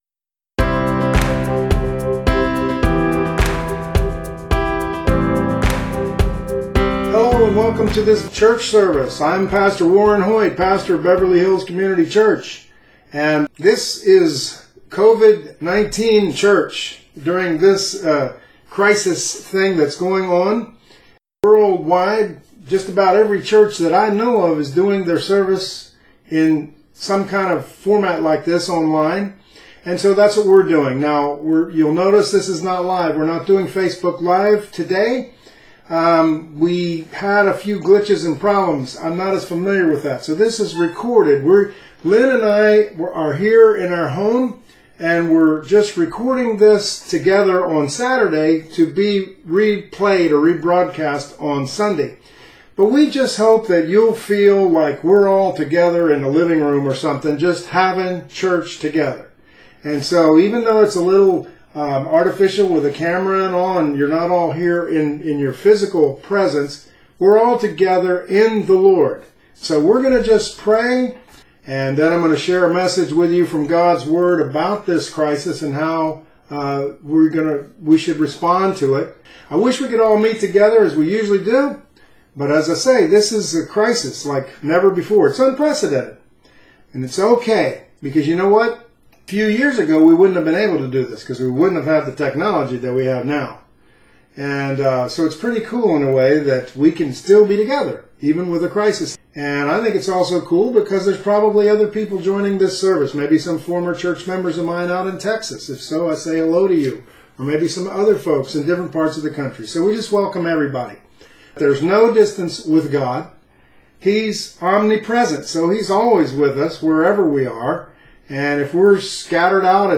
Virtual Church service
In this, I cut out all the hymns and just left the prayers and the message.